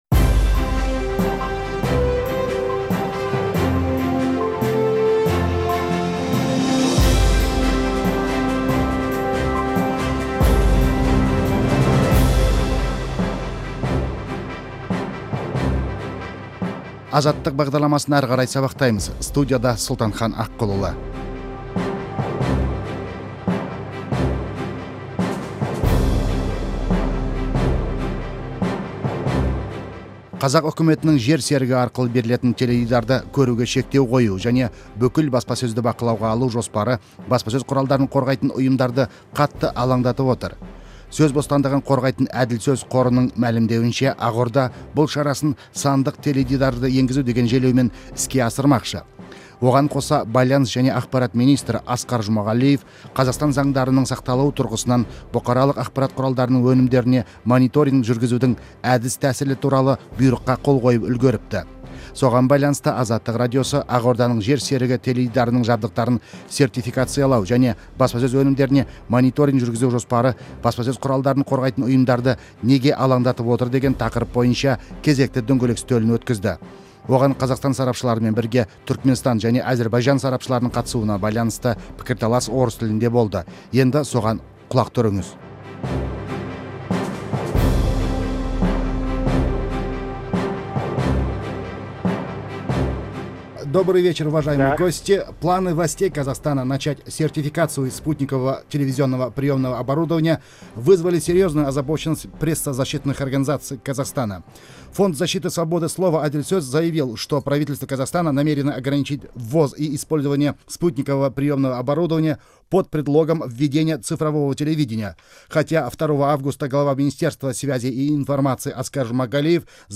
Спутниктік телеарналарға қатысты сұқбатты тыңдаңыз